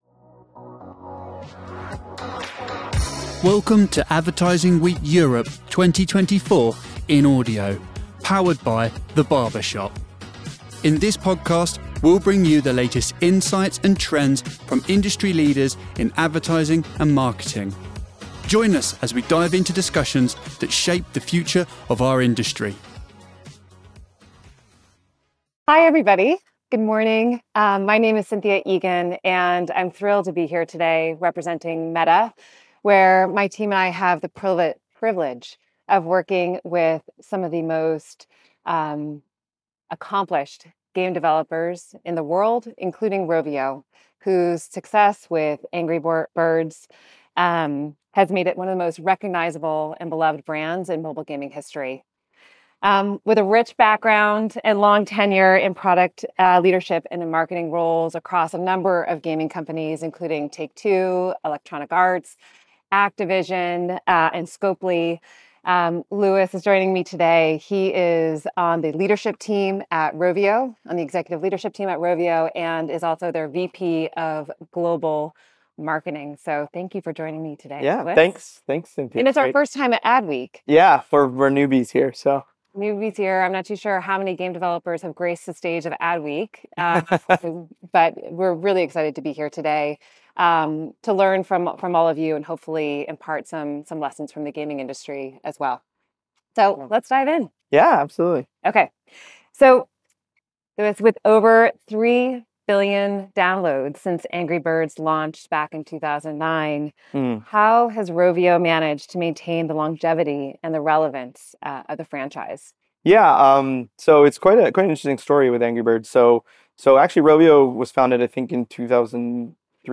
Join an insightful fireside chat focusing on the ways in which the gaming industry has mastered immersive storytelling across multiple media touchpoints.